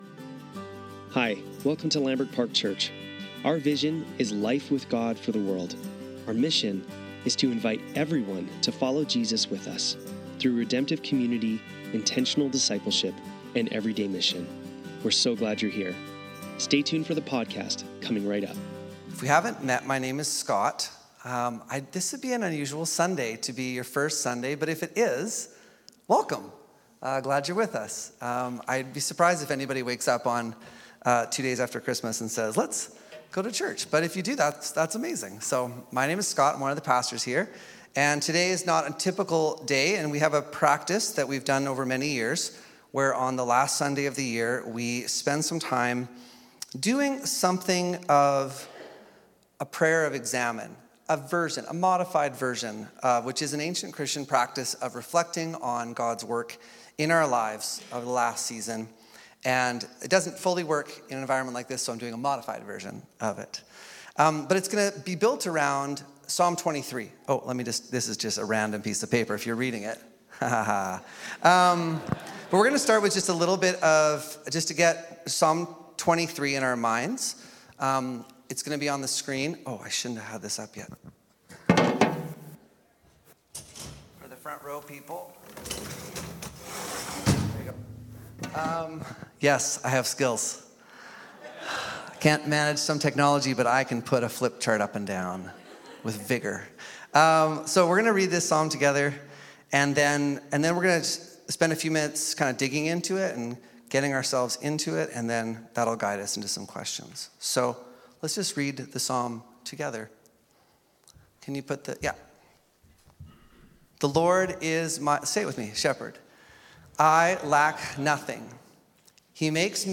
Lambrick Sermons | Lambrick Park Church
Sunday Service - December 29, 2024